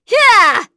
Glenwys-Vox_Attack4.wav